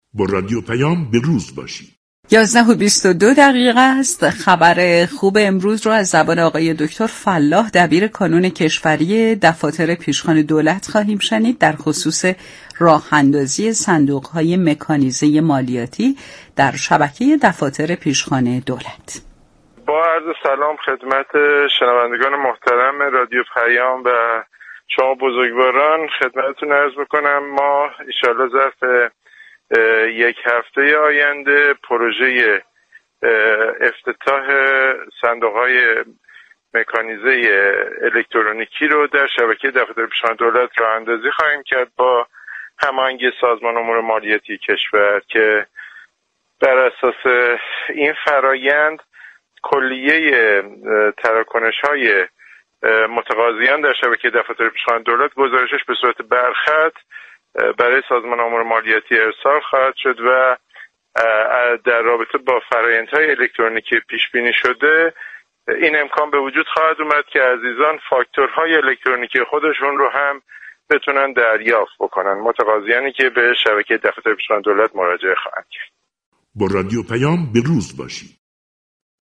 در گفتگو با رادیو پیام